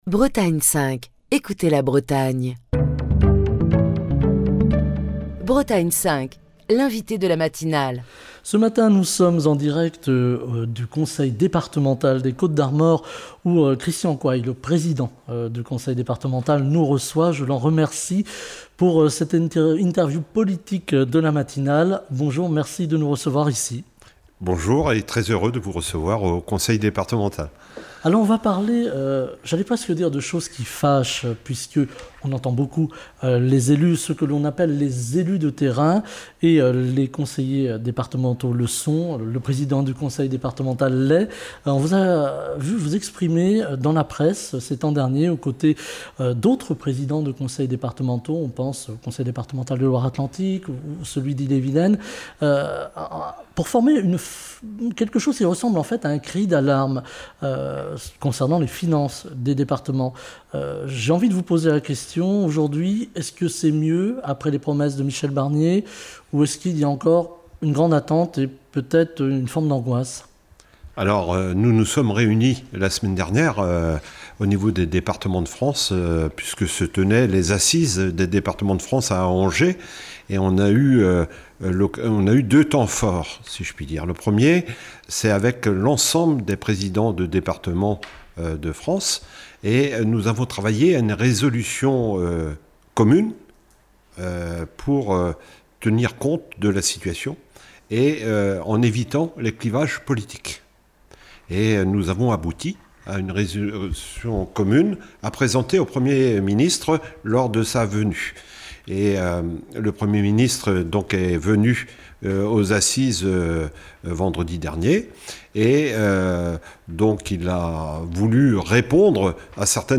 Nous revenons ce matin sur la situation économique des départements, en nous penchant sur les Côtes d'Armor, avec Christian Coail, président du Conseil départemental des Côtes-d'Armor invité de la matinale de Bretagne 5, qui nous reçoit à l'Hôtel du département à Saint-Brieuc.